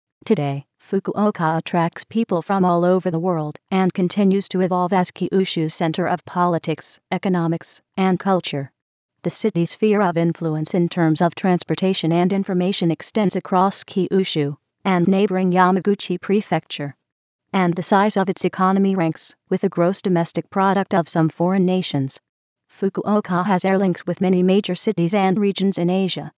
注：上表の音声データはTextAloudによるコンピュータ合成音です。